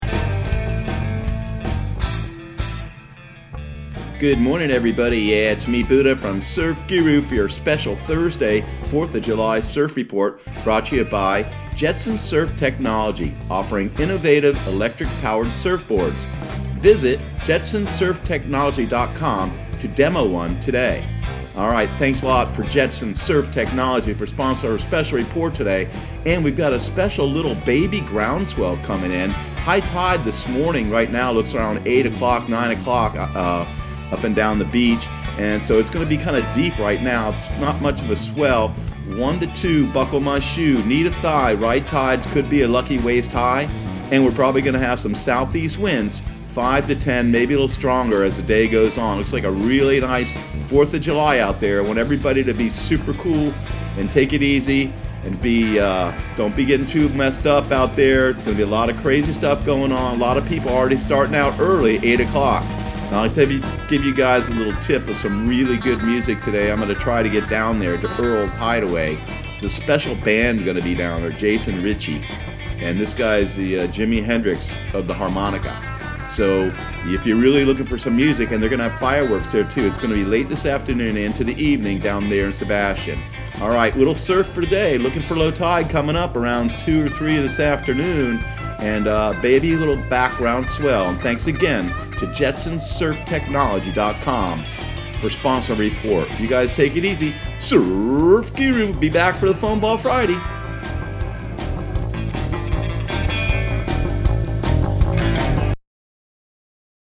Surf Guru Surf Report and Forecast 07/04/2019 Audio surf report and surf forecast on July 04 for Central Florida and the Southeast.